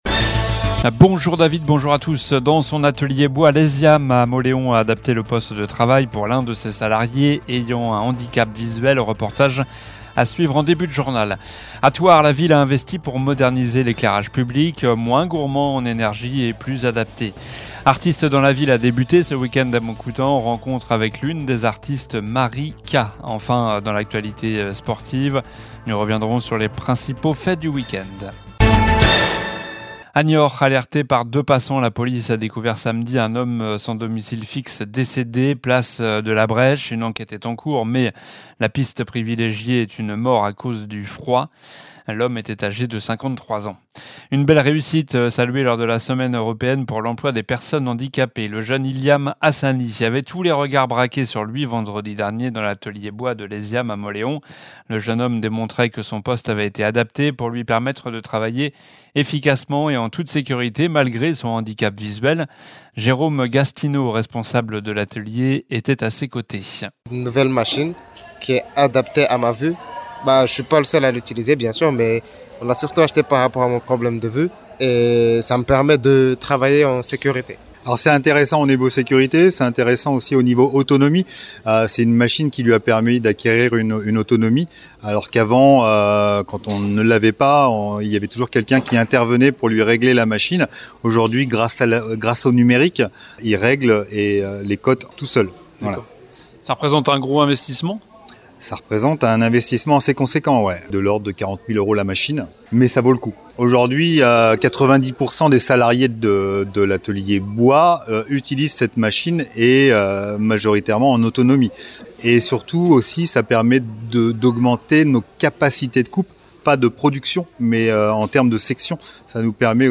Journal du lundi 24 novembre (midi)